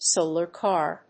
音節sòlar cár